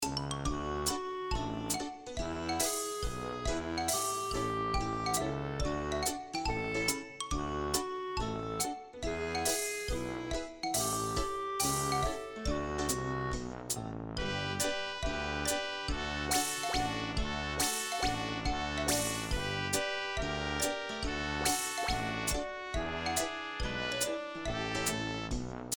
Loop Full Score